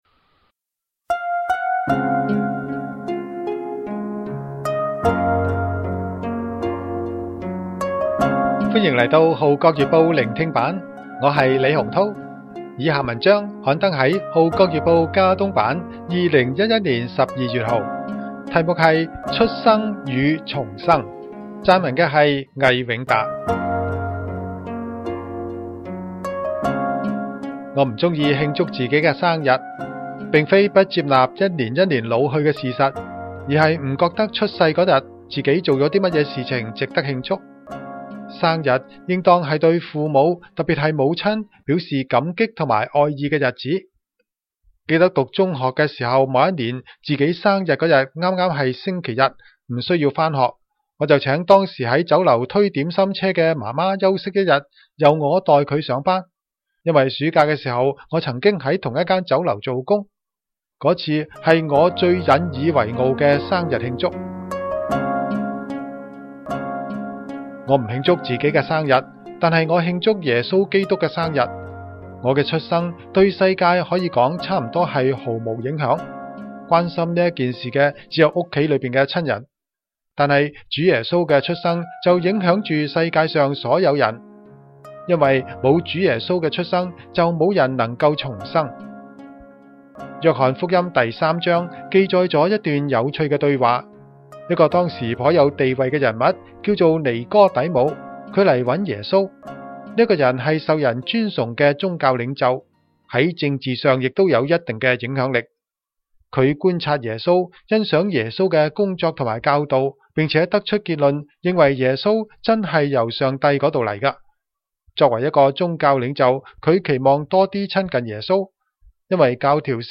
聆聽版/Audio出生與重生 福音恩言